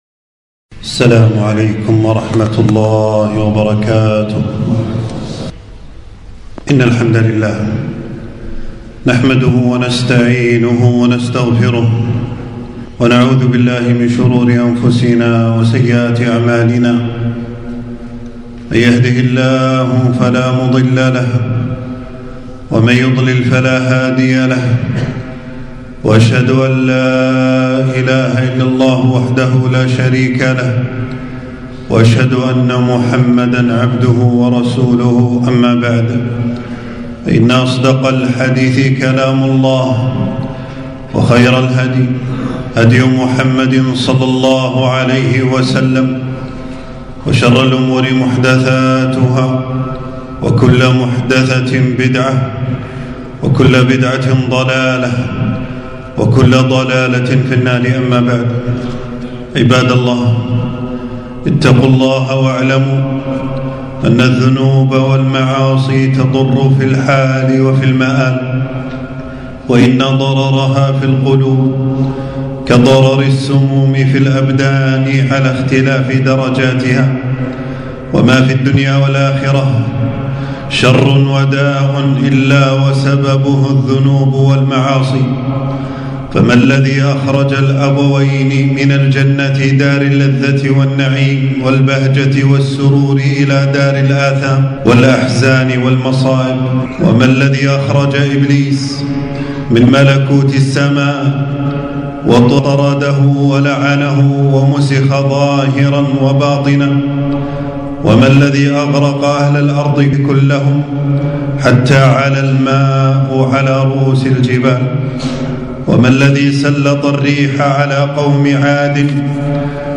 خطبة - آثار الذنوب والمعاصي